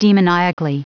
Prononciation du mot demoniacally en anglais (fichier audio)